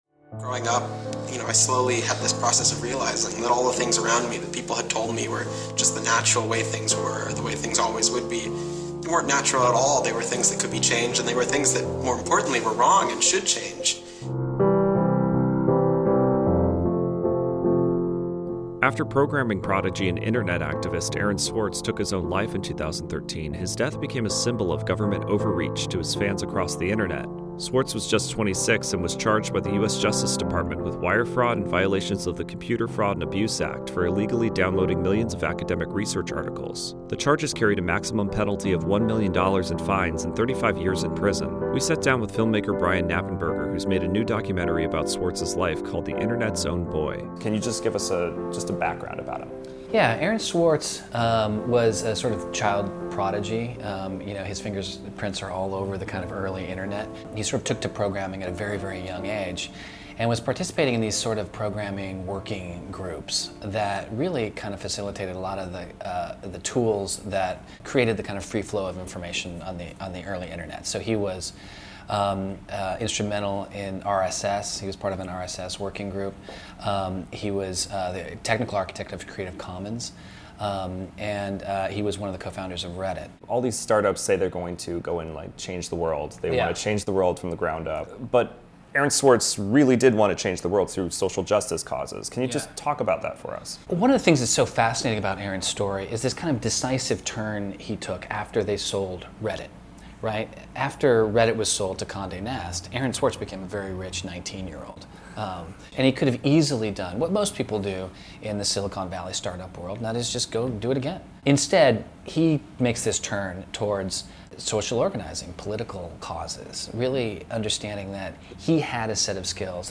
Music by Podington Bear.